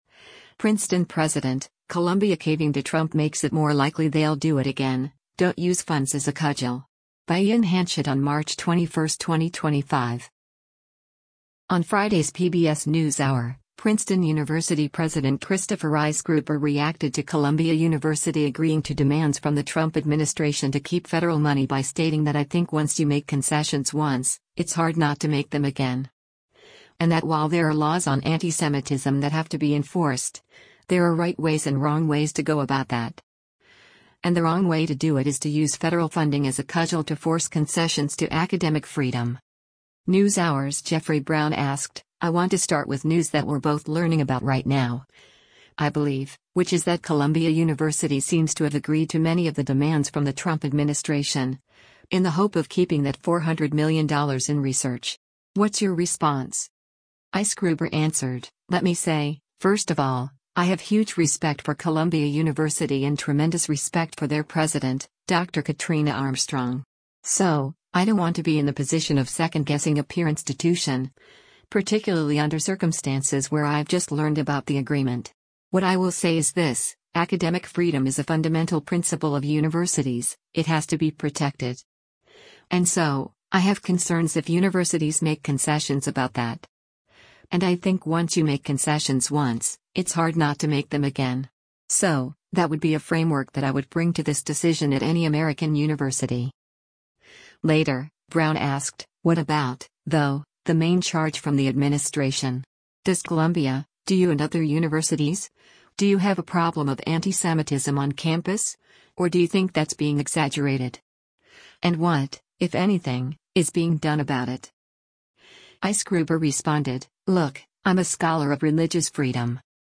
“NewsHour’s” Jeffrey Brown asked, “I want to start with news that we’re both learning about right now, I believe, which is that Columbia University seems to have agreed to many of the demands from the Trump administration, in the hope of keeping that $400 million in research. What’s your response?”